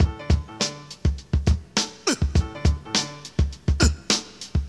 • 102 Bpm High Quality Breakbeat D Key.wav
Free drum groove - kick tuned to the D note. Loudest frequency: 2015Hz
102-bpm-high-quality-breakbeat-d-key-1ny.wav